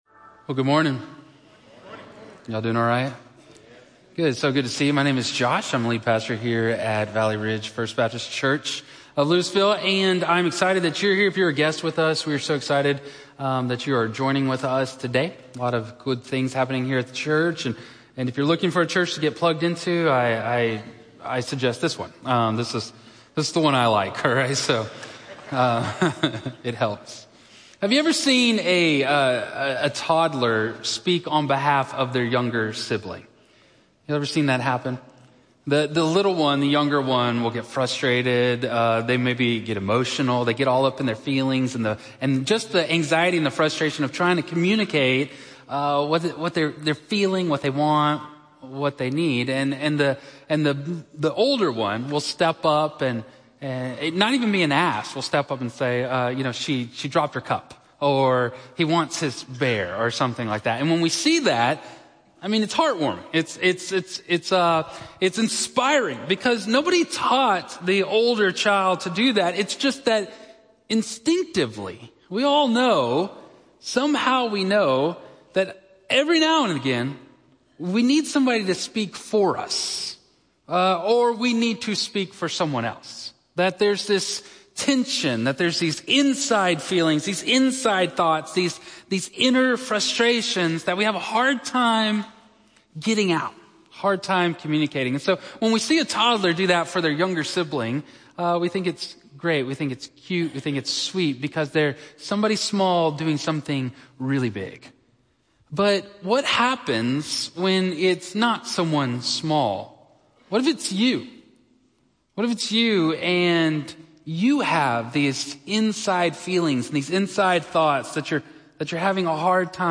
In this profound exploration of Psalm 110, we're invited to discover the transformative concept of Jesus as our eternal priest. The sermon delves into the unique role of Christ, who embodies both king and priest - a combination previously unheard of in Jewish tradition.